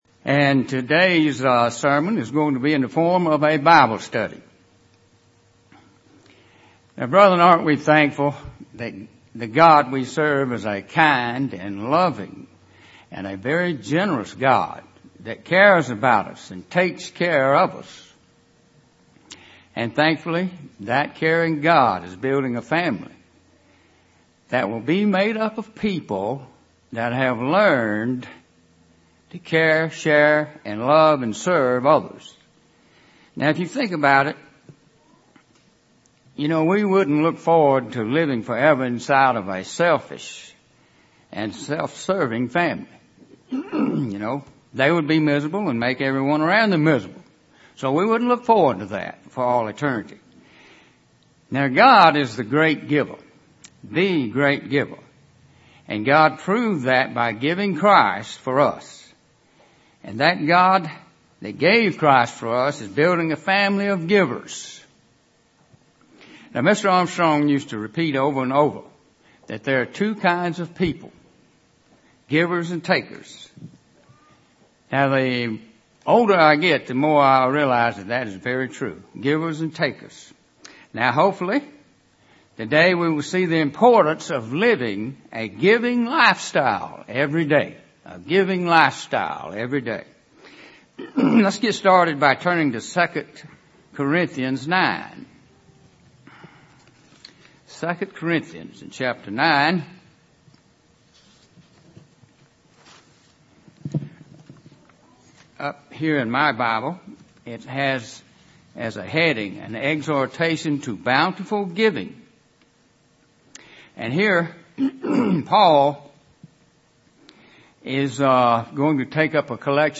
Given in Columbus, GA Central Georgia
UCG Sermon Studying the bible?